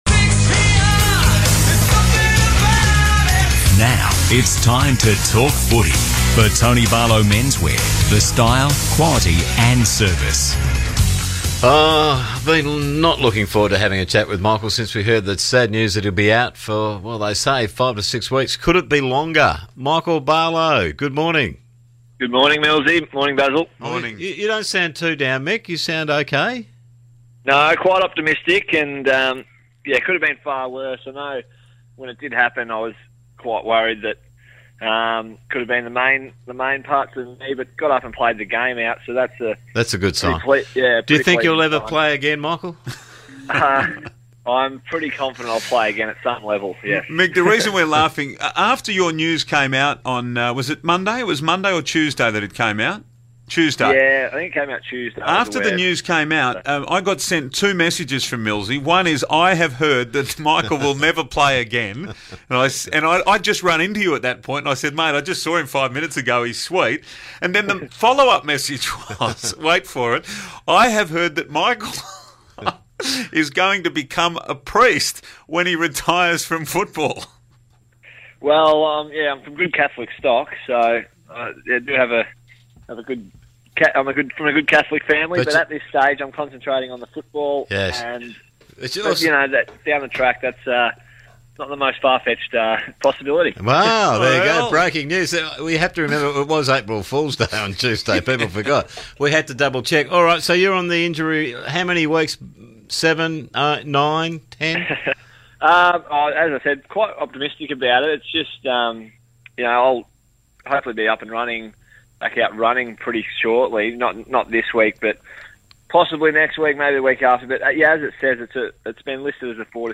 Michael Barlow speaks to 6PR following his knee injury